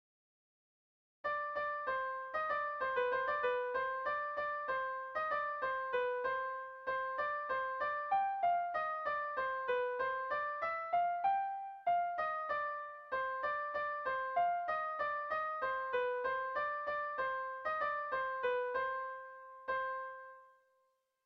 AABA2